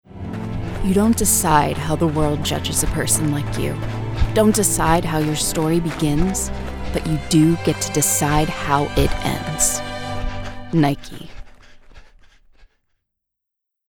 FOOTWEAR COMMERCIAL- Inspirational, Uplifting, Genuine, Real